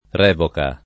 r$voka] s. f. — anche revocazione [revokaZZL1ne] (raro rivocazione [rivokaZZL1ne]): var. oggi meno com., tranne in alcuni usi giur.: revocazione d’una sentenza, d’una donazione, del testamento